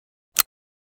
cz52_holster.ogg